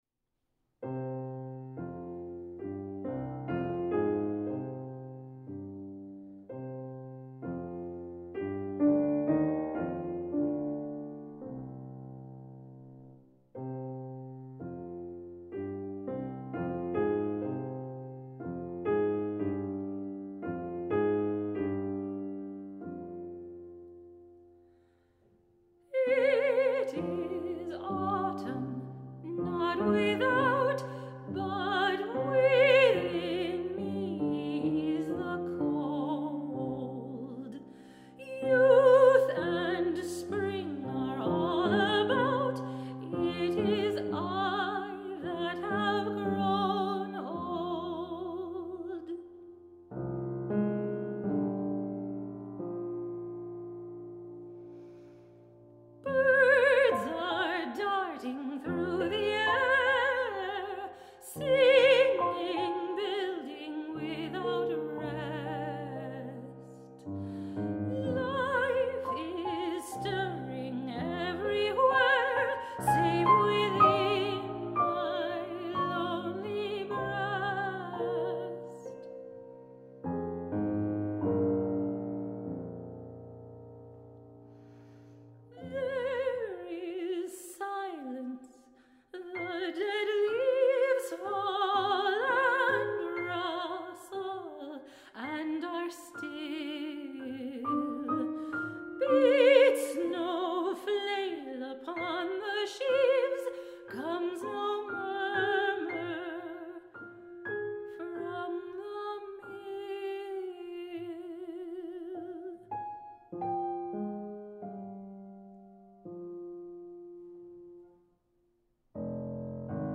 This melancholy song